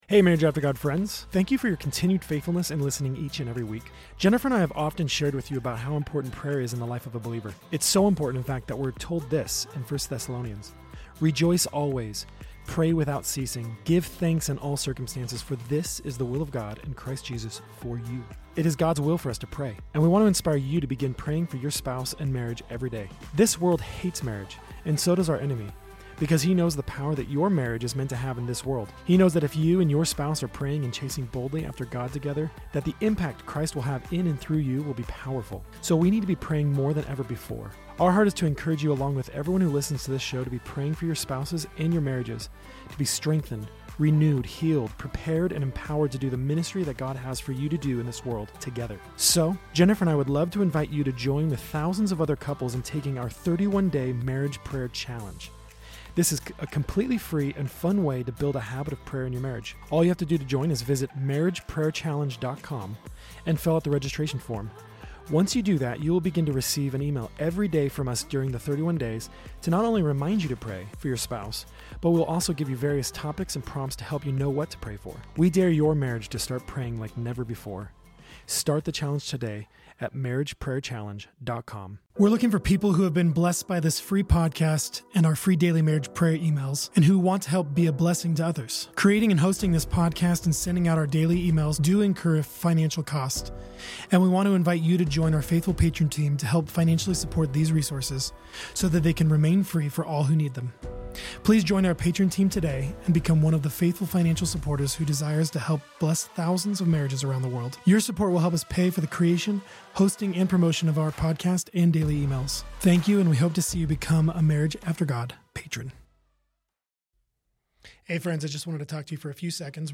This conversation highlights the role of conviction, repentance, and grace in the process of sanctification.